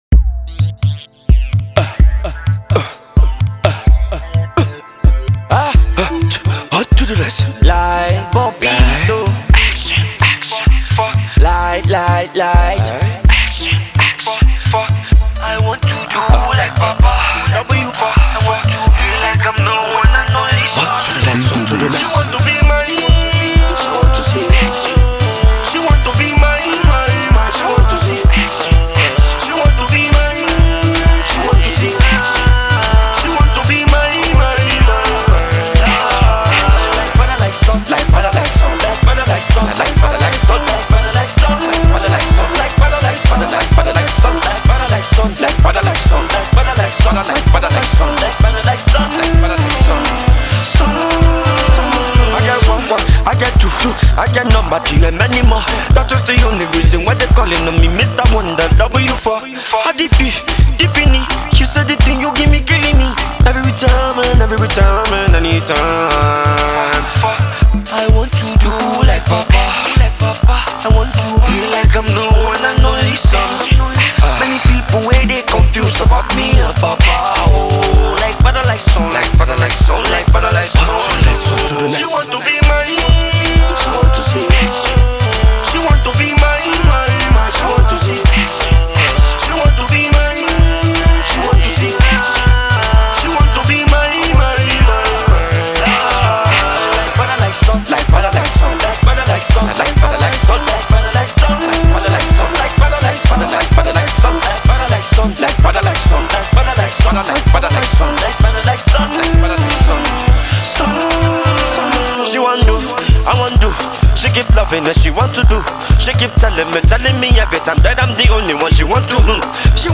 is catchy, entertaining, fun and certainly dance-able